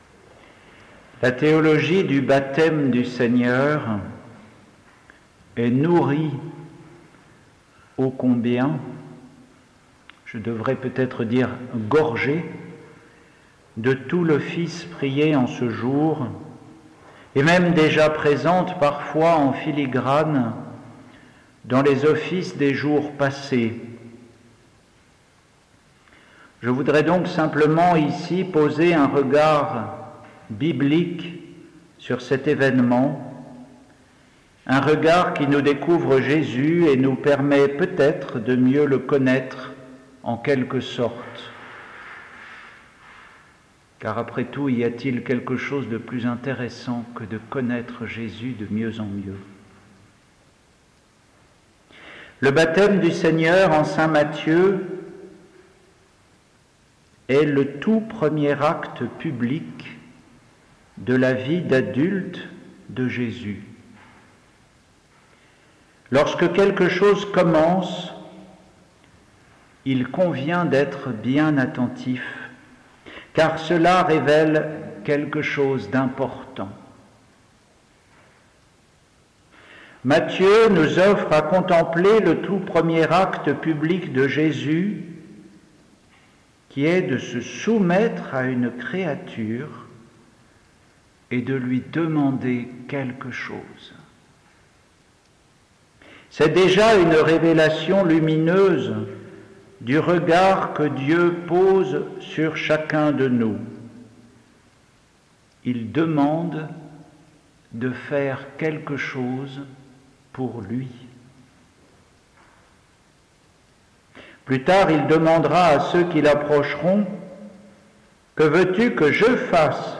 Homélie pour le Baptême du Seigneur